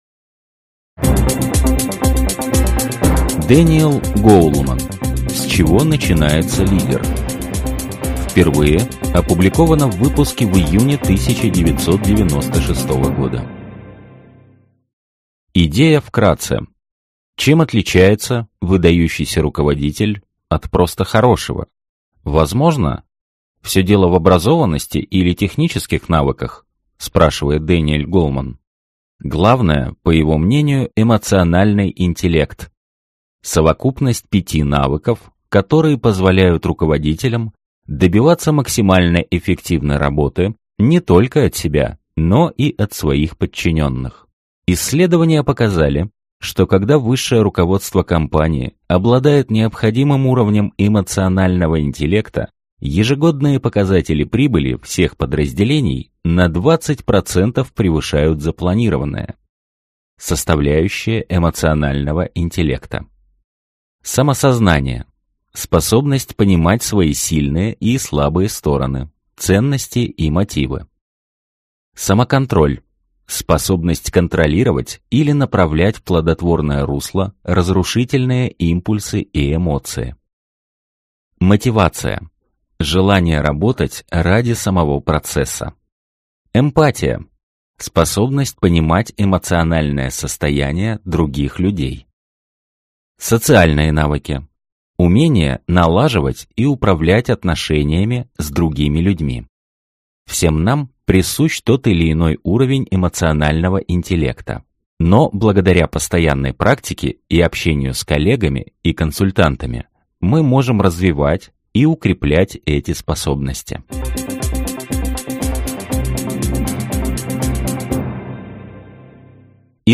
Аудиокнига Лидерство | Библиотека аудиокниг